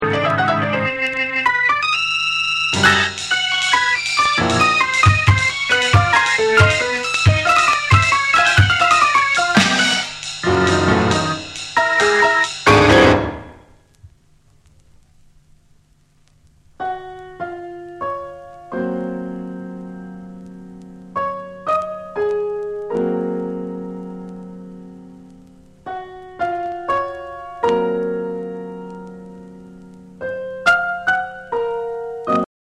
ジャズやアンビエントが交錯する実験的なビートダウンを展開し、ドープかつ幻想的な質感が全編に漂うブレイクビーツを収録。
BREAKBEATS